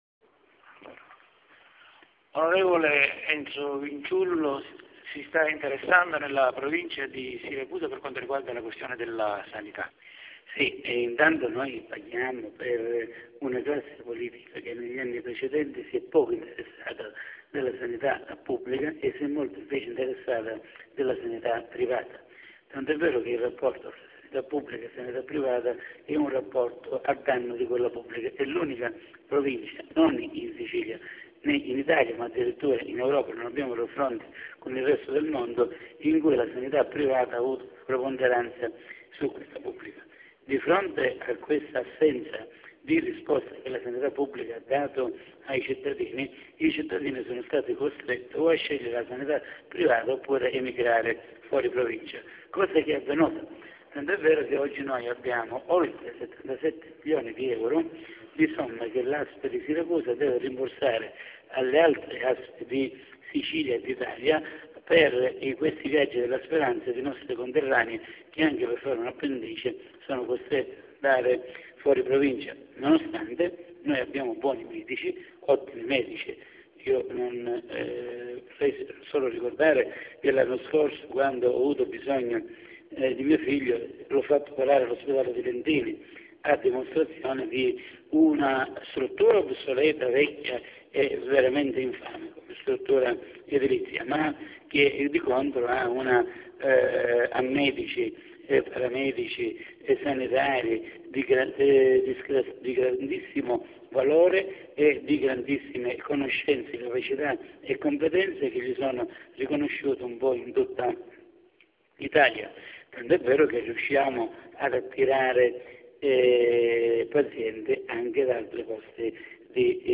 (clicca ed ascolta audio intervista)  è contrario al Piano di rientro del’ASP di Siracusa programmato dalla Regione, ed auspica il Piano di sviluppo.